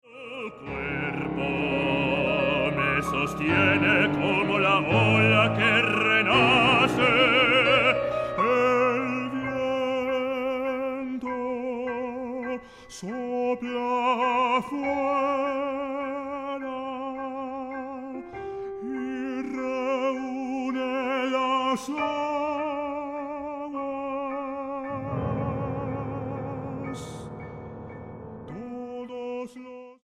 para barítono y piano.